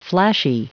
Prononciation du mot flashy en anglais (fichier audio)
Prononciation du mot : flashy